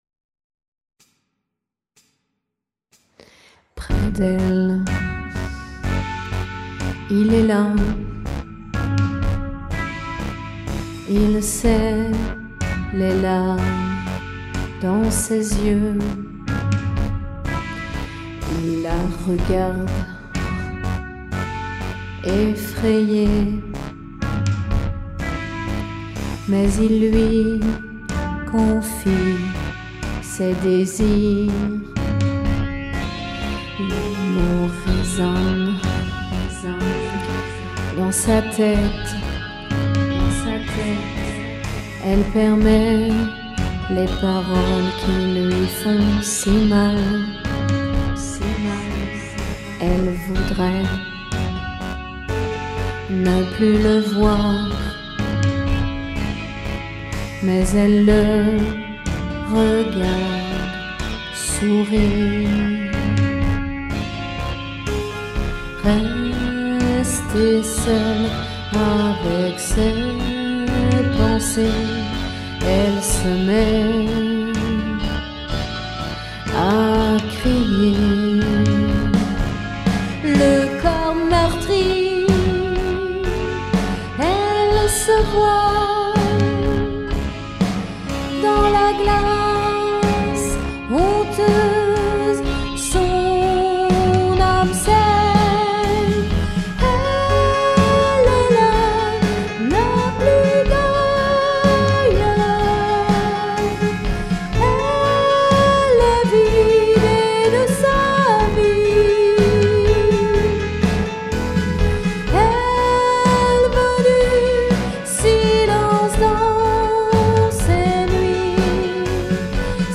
L'un plutot rock et l'autre un peux plus électro.